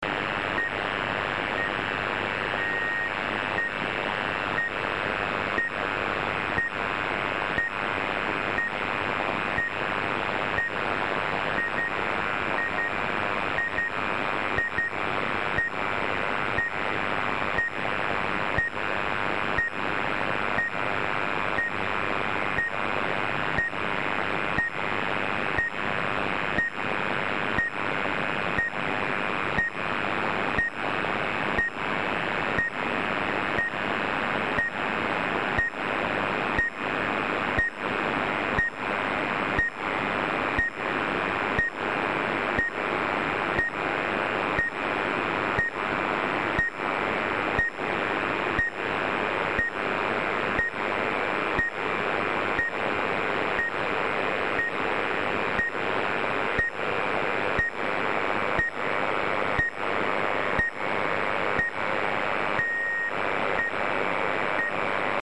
1 и 10 Гц. Длительность сигналов A1X с частотой повторения 1 Гц
A1X signals,